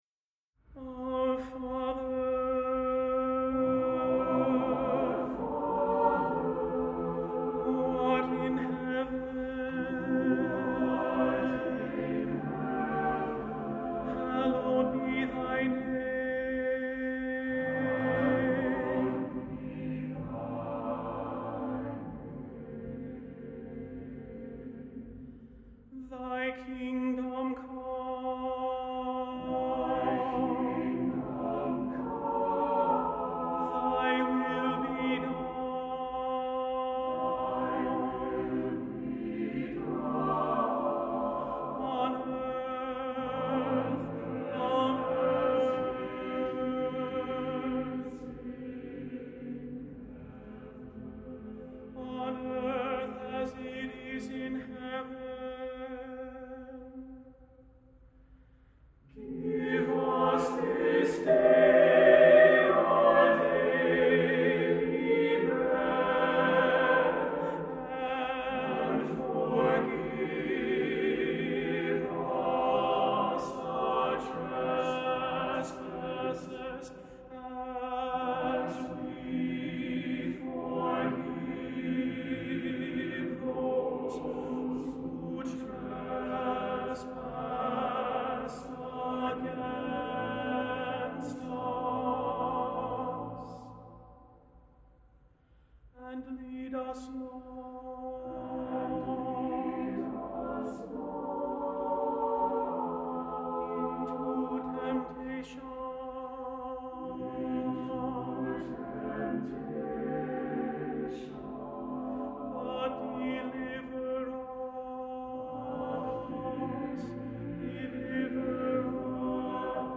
Voicing: SATBB a cappella